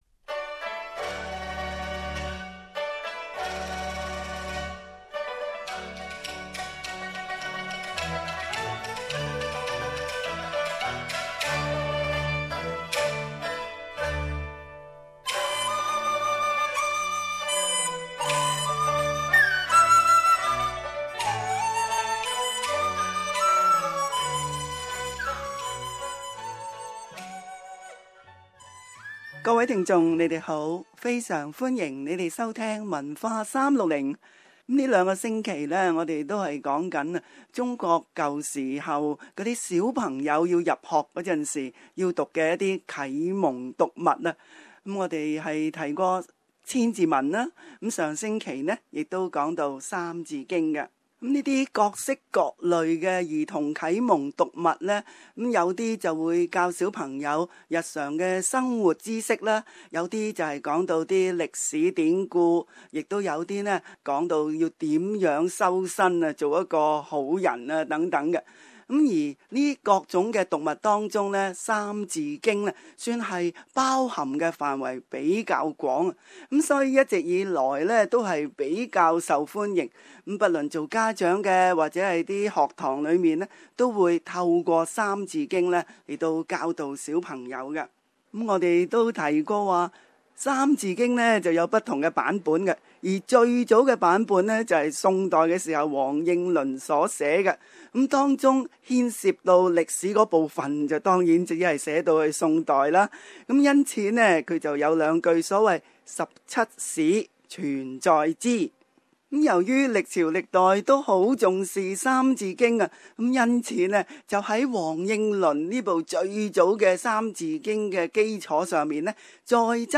Reading Ancient Chinese Writing Source: Getty